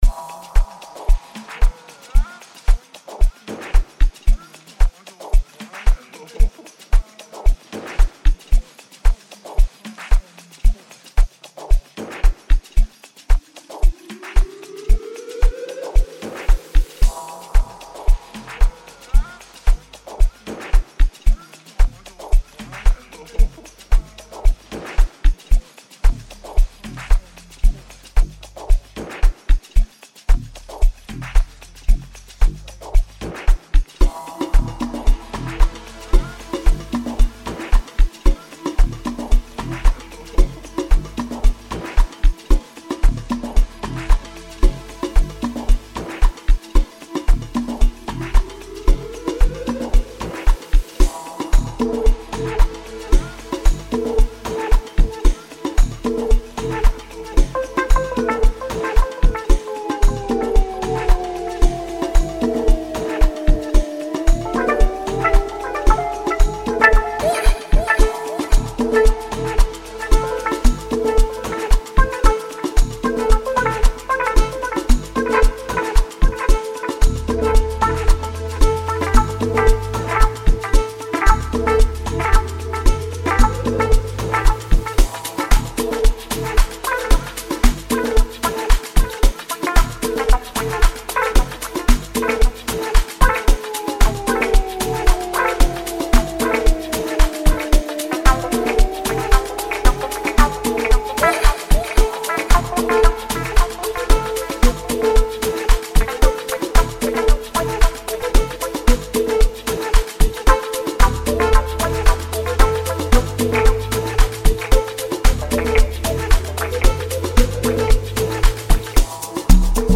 infectious Amapiano tune
piano
The song is simplistic in design and flawlessly executed